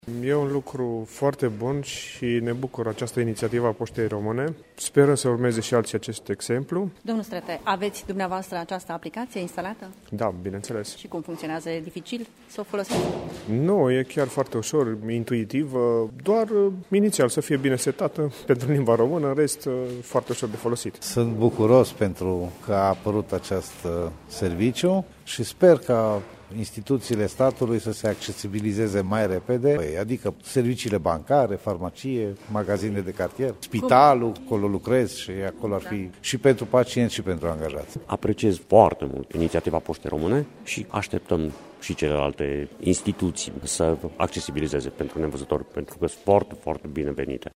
Pentru lansarea sistemului au venit astăzi, la sediul Oficiului nr 1 al Poștei din Târgu Mureș, reprezentanții Asociației Nevăzătorilor care deja au învățat să folosească aplicația.